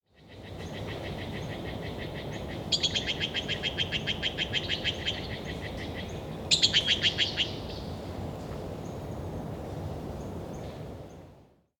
Yellow billed blue magpie